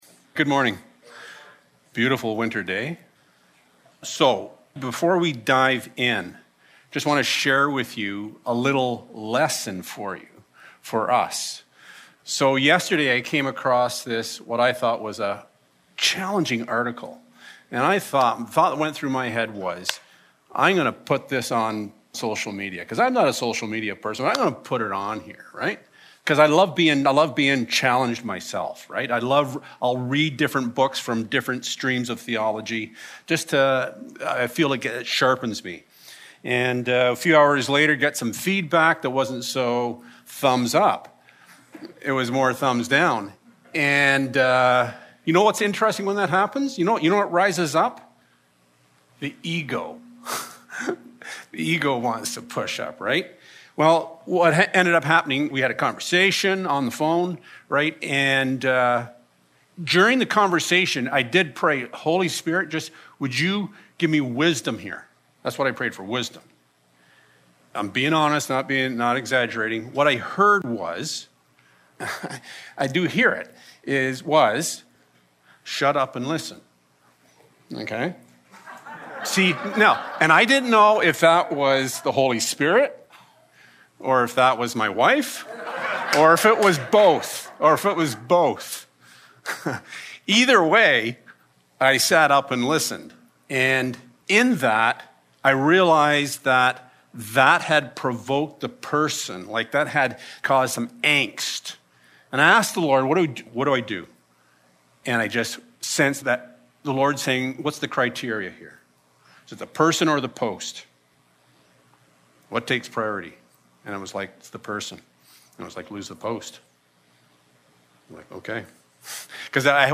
Matthew 5:13-16 Service Type: Sunday Morning Influence.